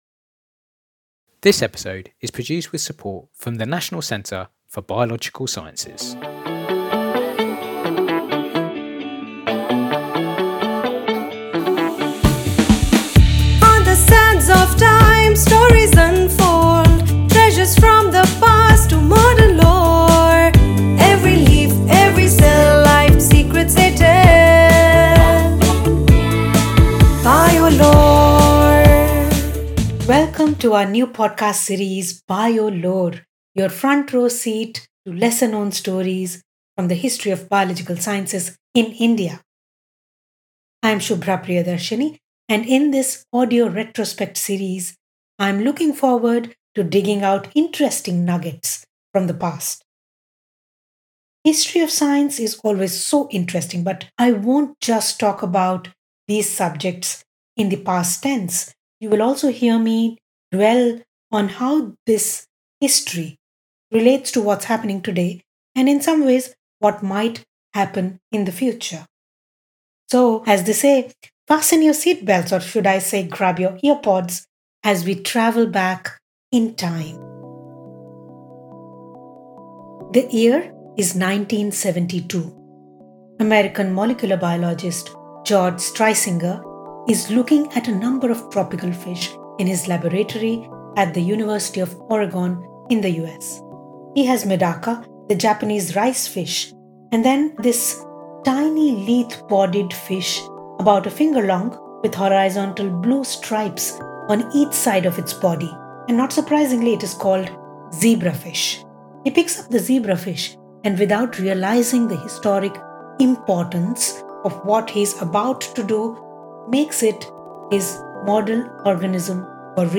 This richly researched audio retrospect draws on expert insights, science archives, oral histories, literature reviews and field reportage.